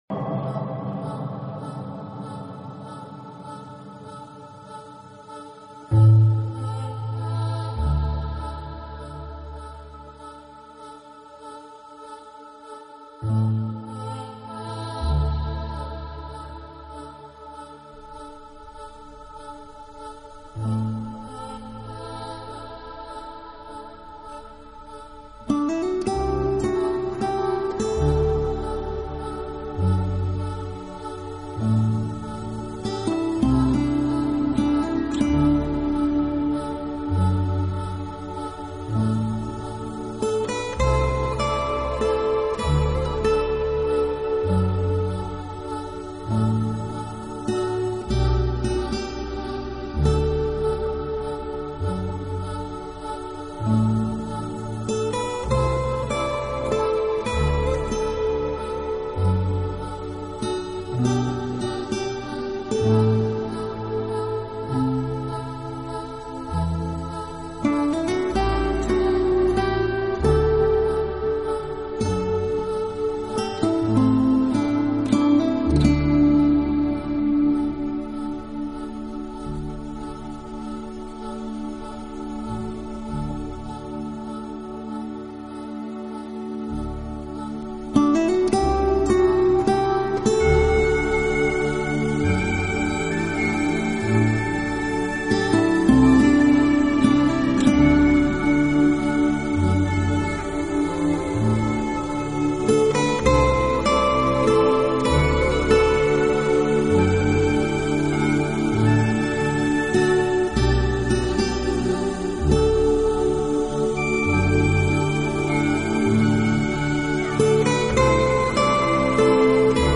音乐类型:  New  Age
每首曲目之间都配以自然音效，并重新进行了精心的混编。